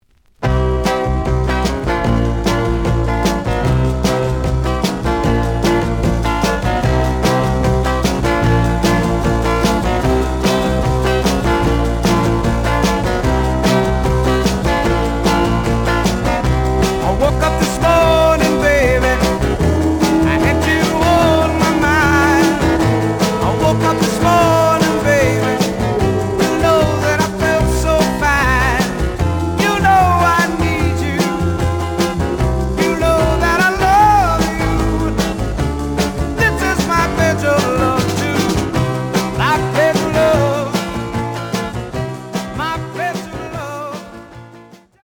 The audio sample is recorded from the actual item.
●Genre: Soul, 60's Soul
Some damage on both side labels. Plays good.)